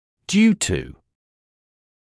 [‘djuːtə][‘дьюːтэ]из-за, по причине, вследствие